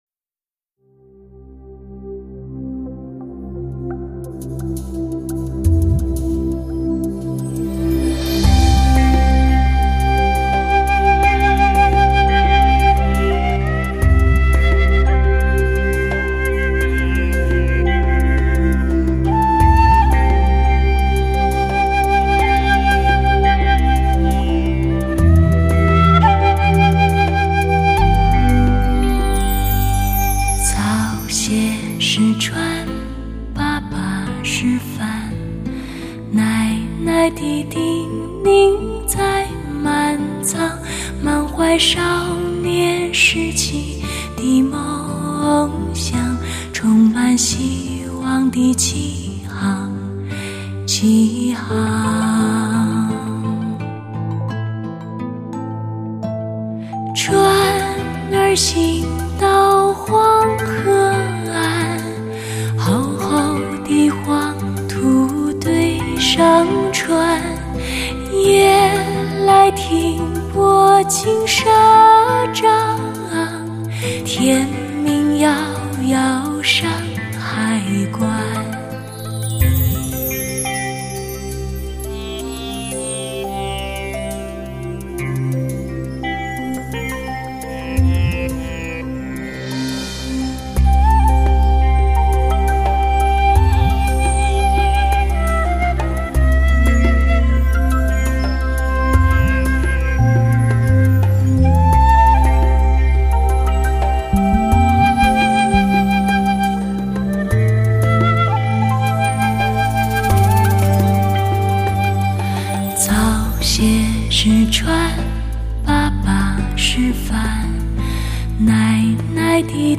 发烧HI-FI感恩天碟